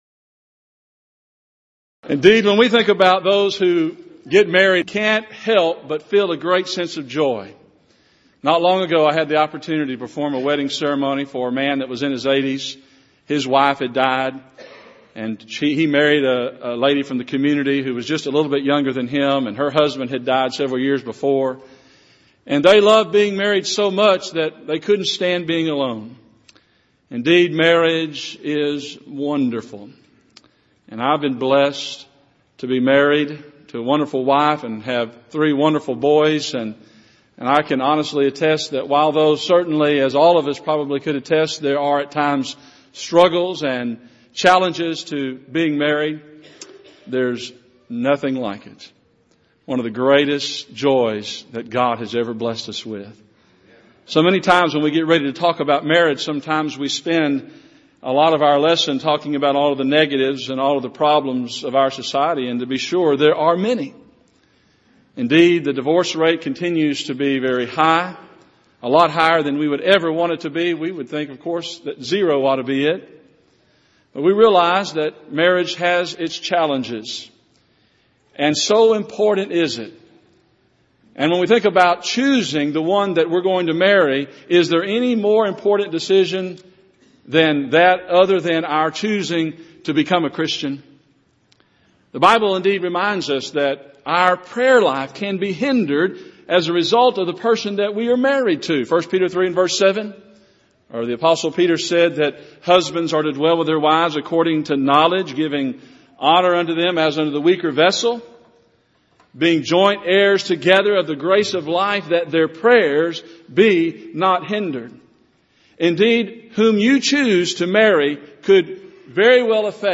Event: 28th Annual Southwest Lectures
lecture